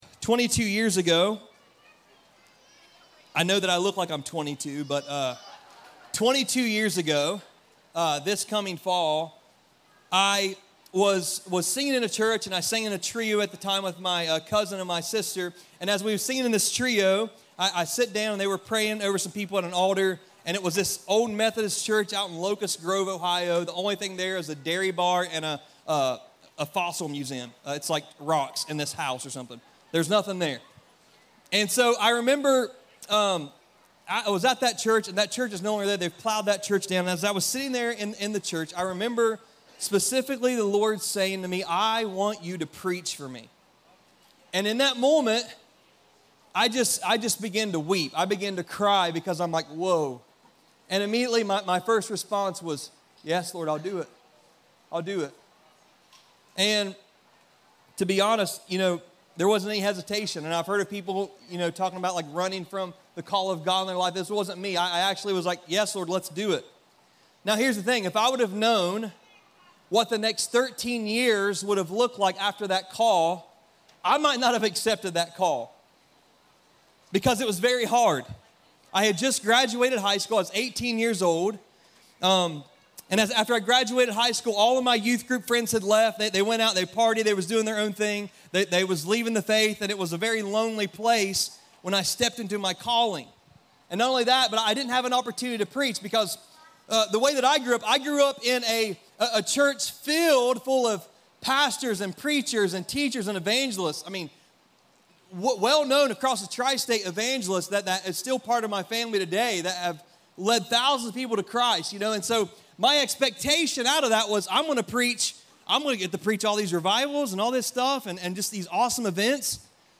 Burning The Plow - Stand Alone Messages ~ Free People Church: AUDIO Sermons Podcast